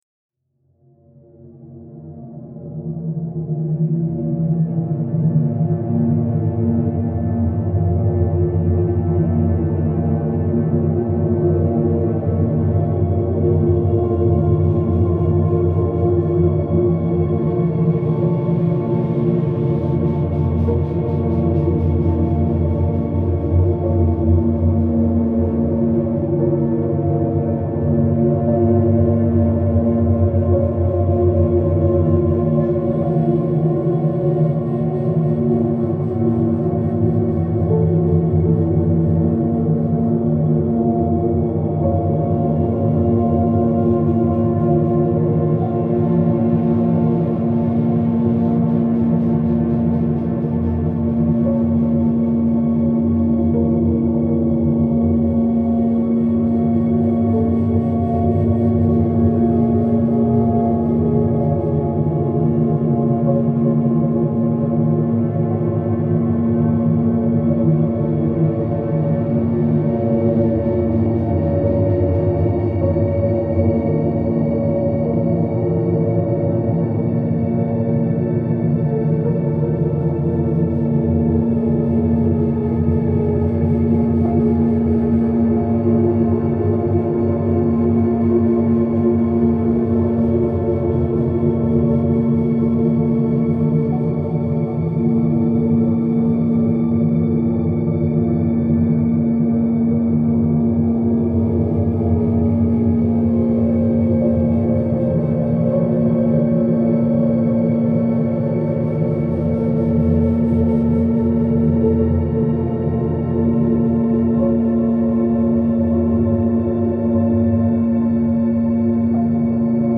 gloomy.mp3